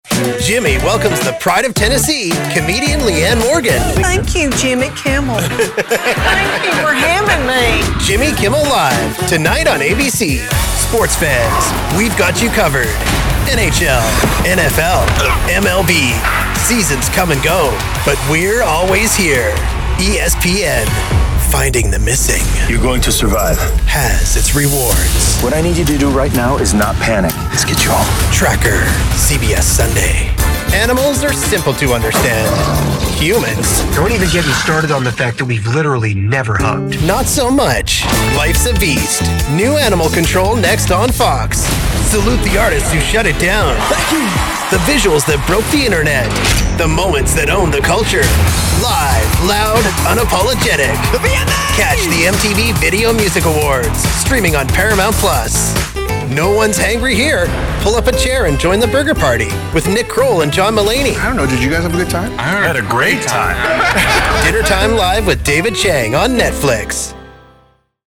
Male
My voice has "today's sound". It's young, fresh, cool, natural, conversational, relatable. I can do anything from laid back to hard sell and excited. I have a slight raspy and deep voice but can deliver upbeat young sounding copy with ease. I can do a cool, hip radio imaging voice from excited to smooth "mtv" type deliveries.
Radio / TV Imaging
Promo Demo For Tv Shows